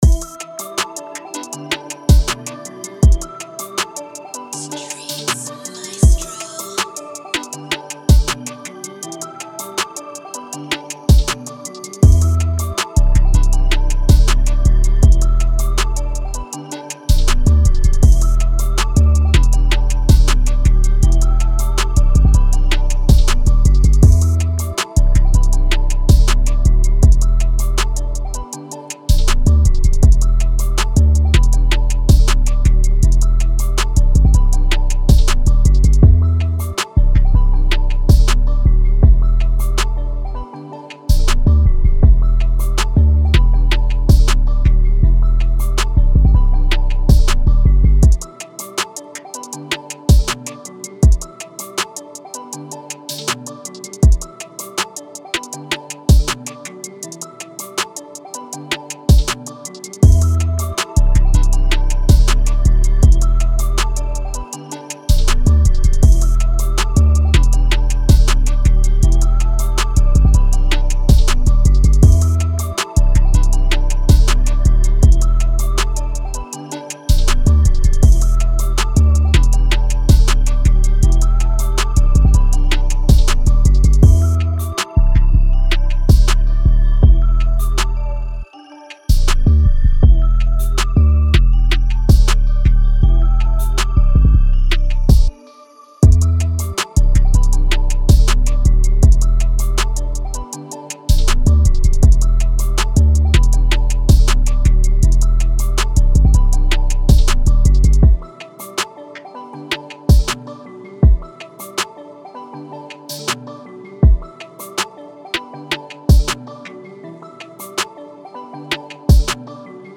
Moods: Bouncy, carefree, light
Genre: Rap
Tempo: 160
BPM 130
bouncy, carefree, light